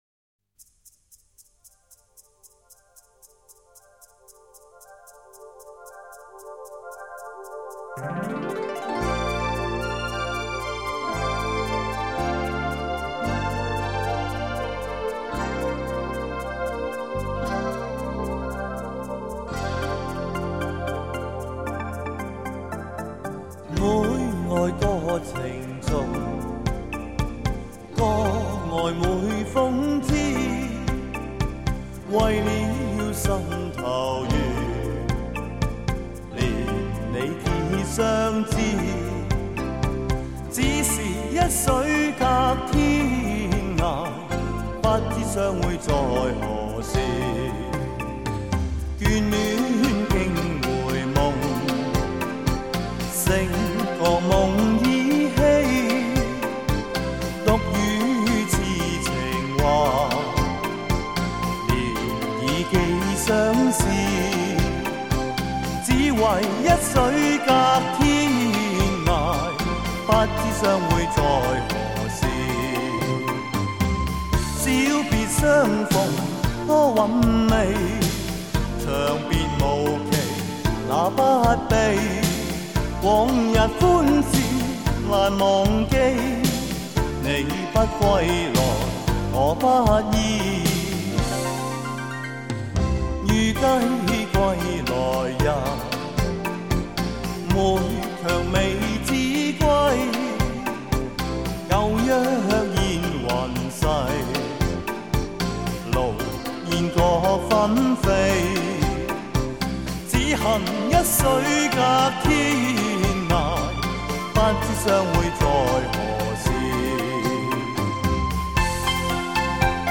香港歌坛80年代活跃的歌手，嗓音浑厚独特，别具韵味。